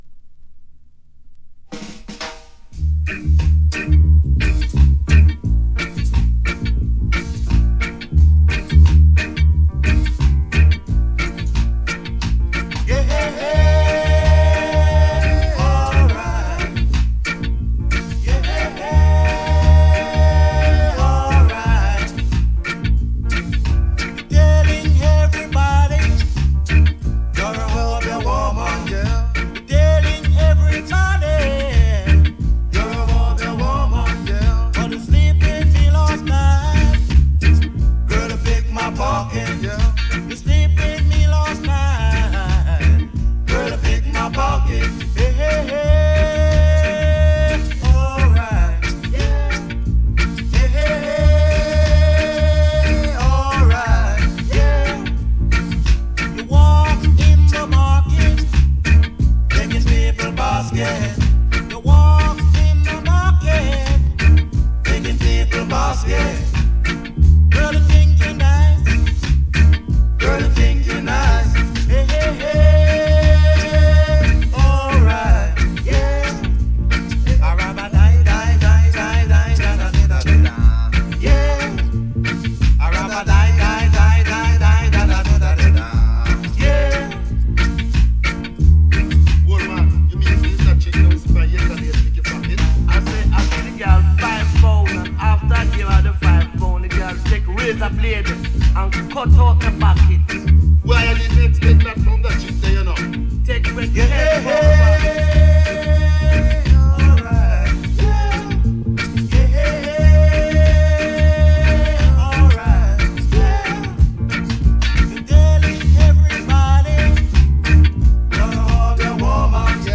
Reggae tunes from '69